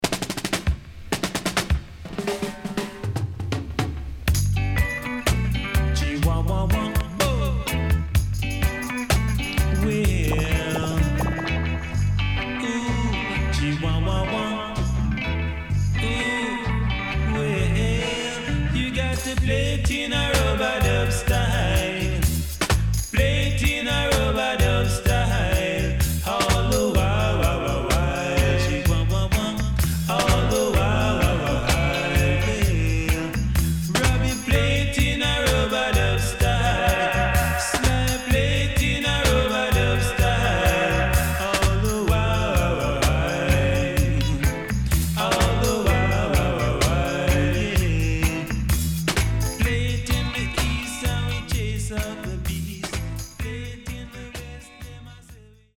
【12inch】
Nice Lovers Vocal & Good Dancehall
SIDE A:少しチリノイズ入りますが良好です。